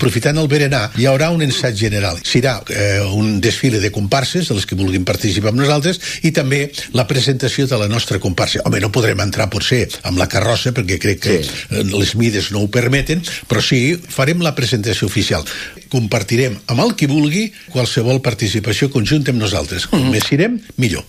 en una entrevista al magazine a l’FM i +